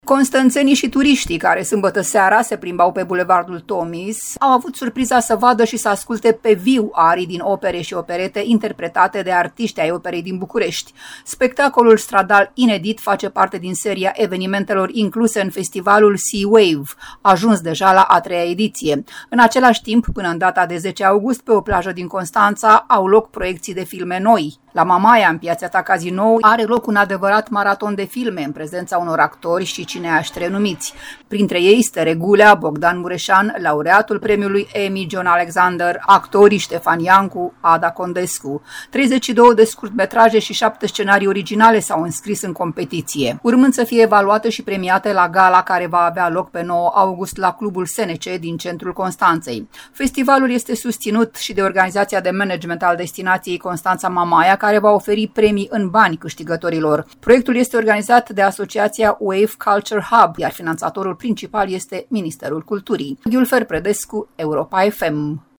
Constănțenii și turiștii care, sâmbătă seara, se plimbau pe Bulevardul Tomis au avut surpriza să vadă și să asculte pe viu arii din opere și operete interpretate de artiști ai operei din București.